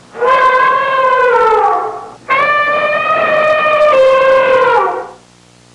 Bull Elephant Sound Effect
Download a high-quality bull elephant sound effect.
bull-elephant-2.mp3